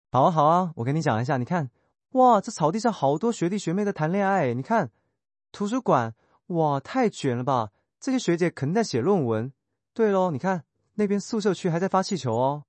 5、音色自然：该模型使用海量语音数据训练，可以根据原始语音内容自适应调节语气和表现力的拟人音色
描述：不会翘舌音的设计师。